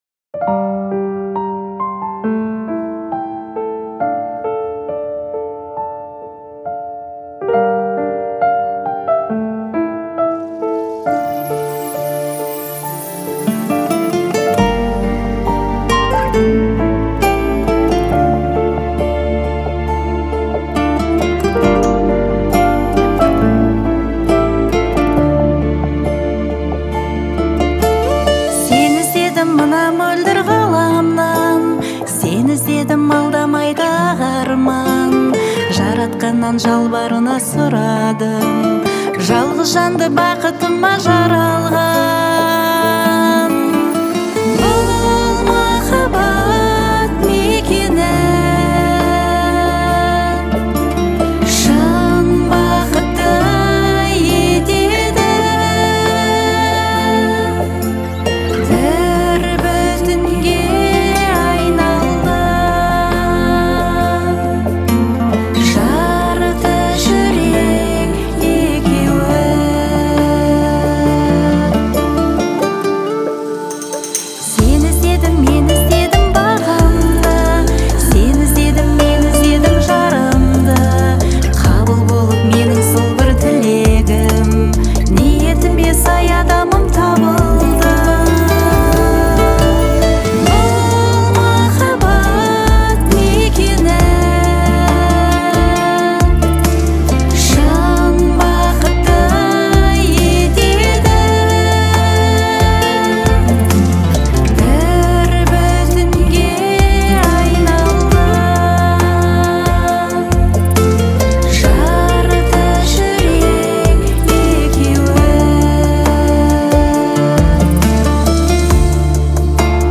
Казахская музыка